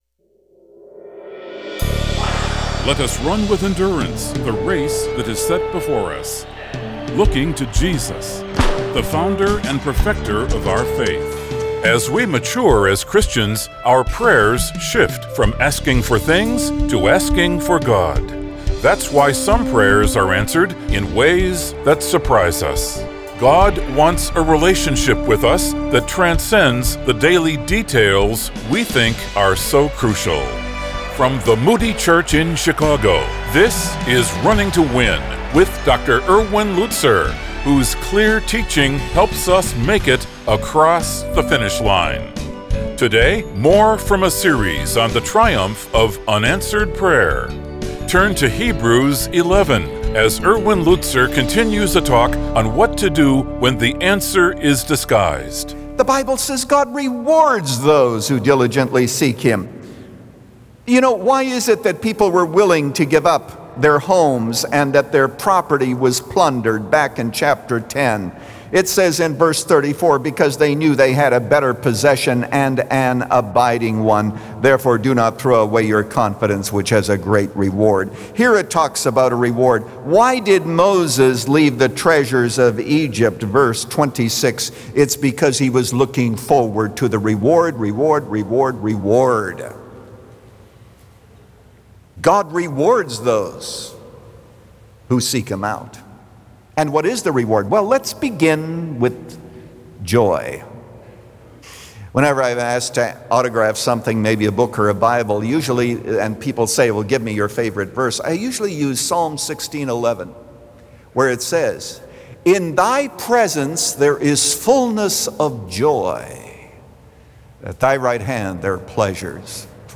When The Answer Is Disguised – Part 2 of 3 | Radio Programs | Running to Win - 15 Minutes | Moody Church Media